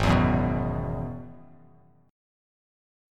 Listen to G#m7#5 strummed